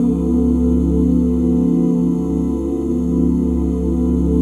OOC#SHARP9.wav